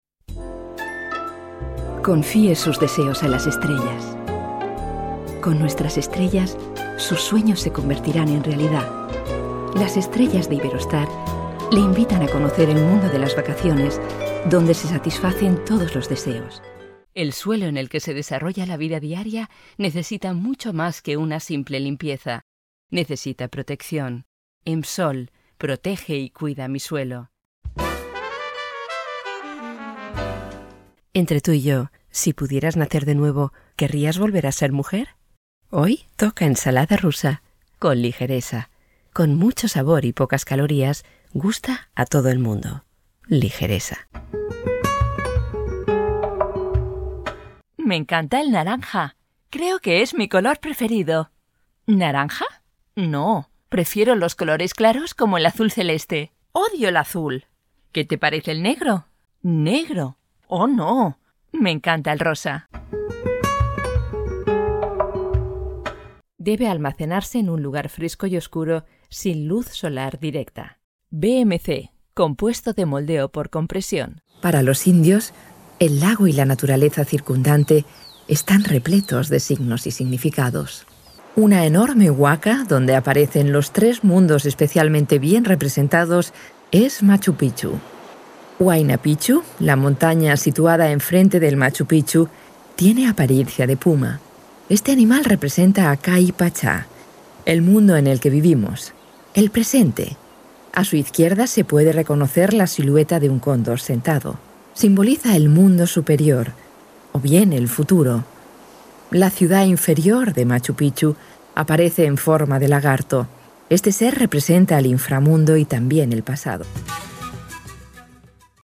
Klare saubere Stimme.
SPANISCH: Elegantes Hochspanisch. Perfekt hörverständliche Aussprache.
Spanisch Sprecherin.
Kastilianisch und Iberisches Spanisch.
kastilisch
Sprechprobe: Werbung (Muttersprache):